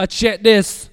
VOX SHORTS-1 0024.wav